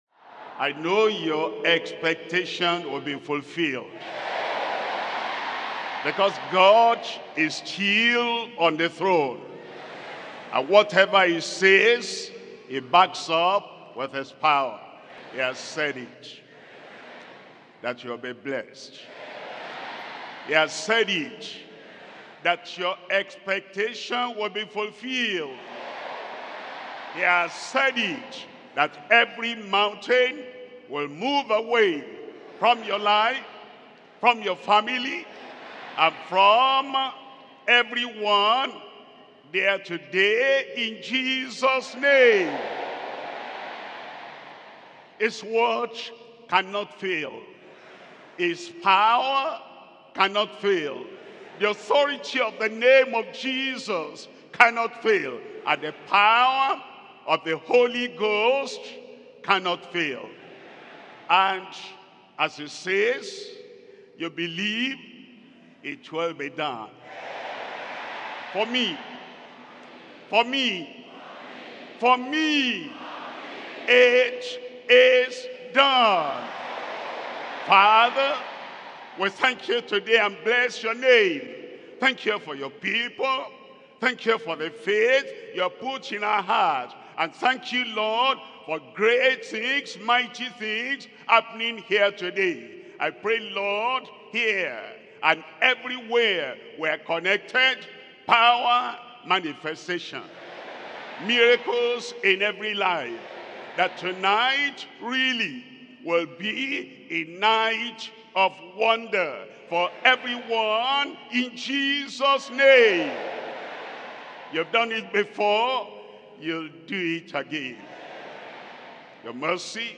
SERMONS – Deeper Christian Life Ministry Australia